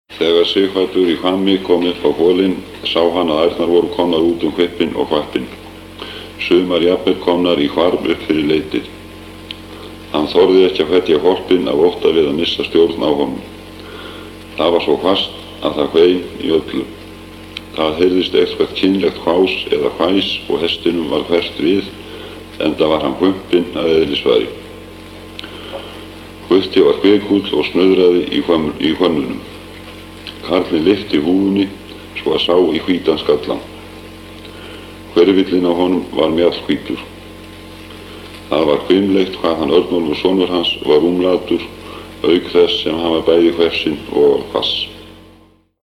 Mállýskudæmi
Vestur-Skaftfellingar
Dæmi 3: Karl les texta um Sighvat í Hvammi á 9. áratug 20. aldar (Málhafi nr. 8 í Mállýskudæmum bls. 36):